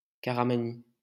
Caramany (French pronunciation: [kaʁamani]